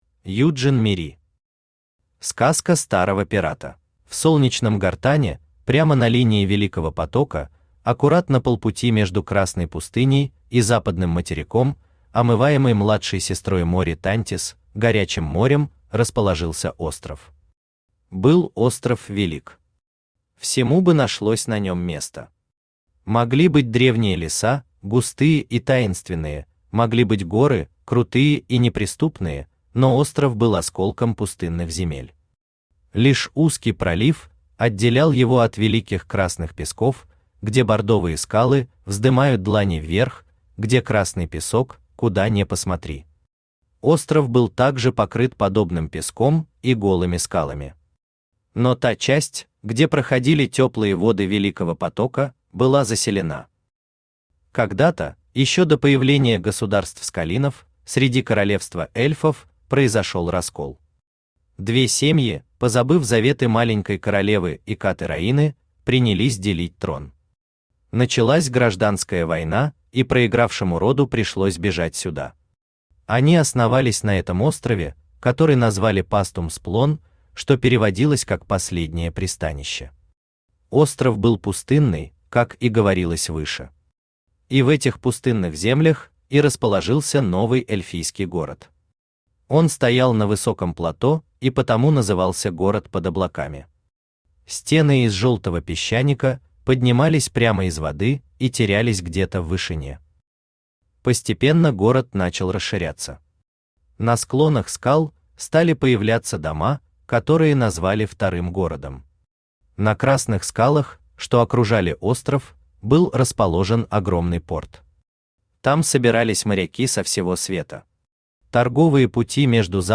Аудиокнига Сказка старого пирата | Библиотека аудиокниг
Aудиокнига Сказка старого пирата Автор Юджин Мири Читает аудиокнигу Авточтец ЛитРес.